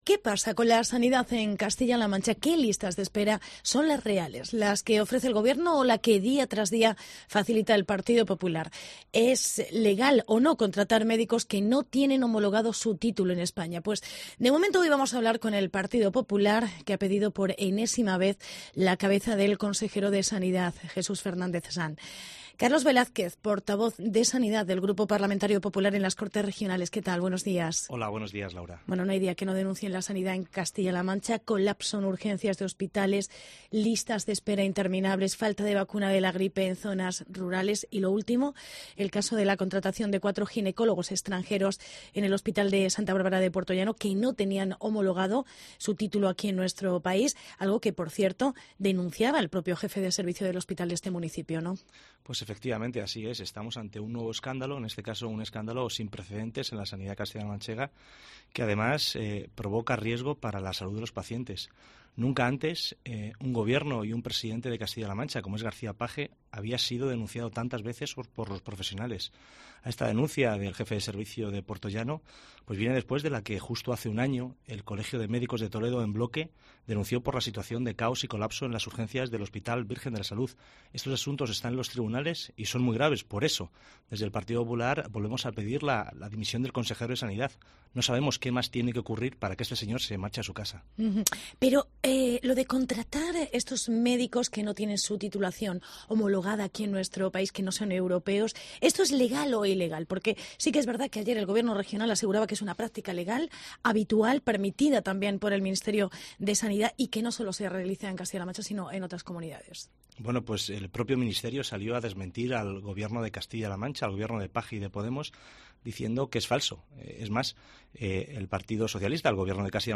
Entrevista con el diputado portavoz de sanidad: Carlos Velázquez